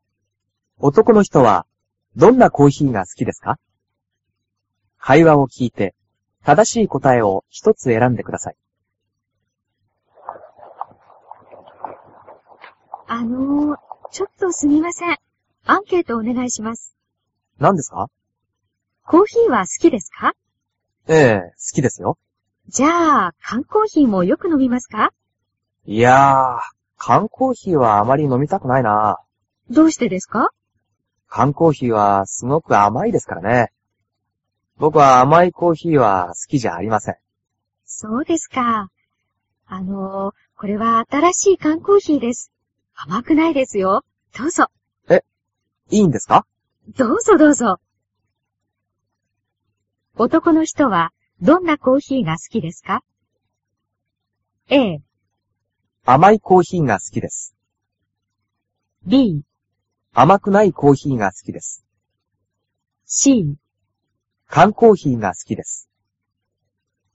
Otoko no hito wa donna koohii ga sukidesuka. Kaiwa wo kiite tadashii kotae wo hitotsu erande kudasai.